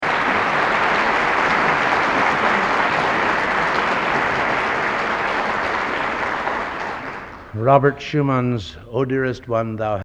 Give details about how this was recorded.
Collection: End of Season, 1964 Location: West Lafayette, Indiana Genre: | Type: End of Season